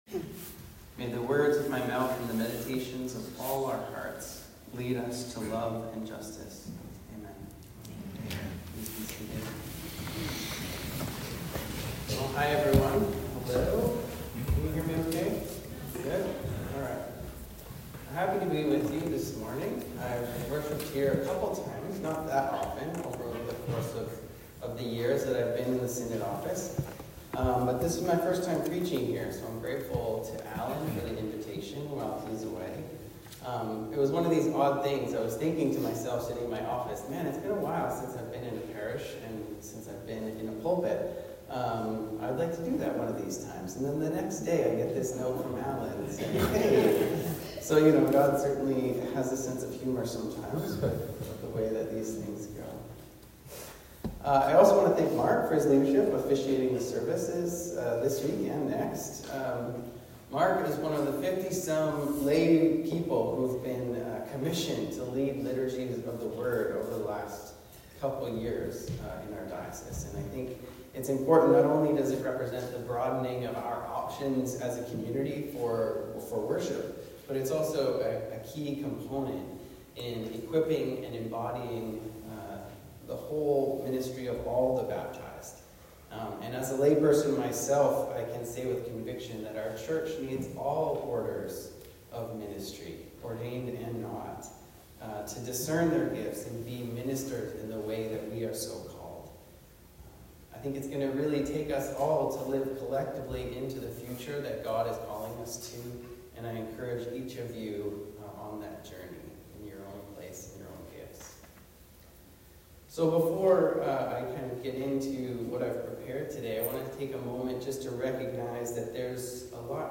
Sermon 10 November 2024